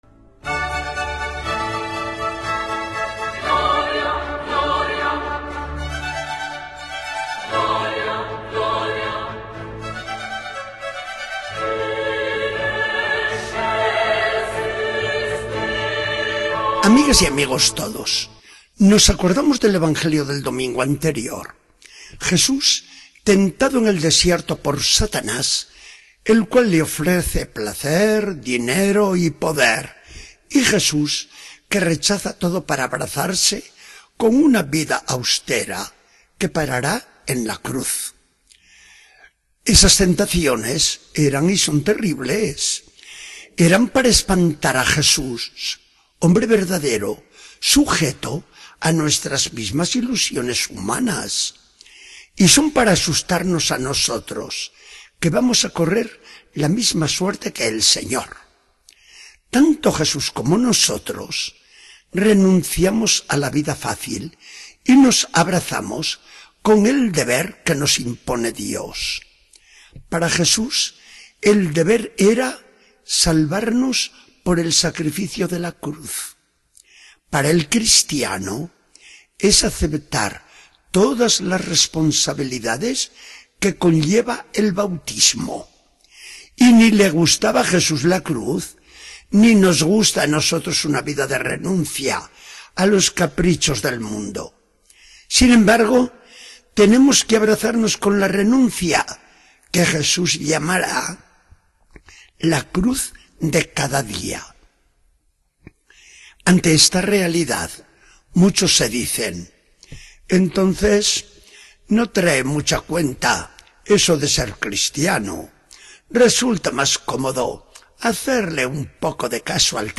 Charla del día 16 de marzo de 2014.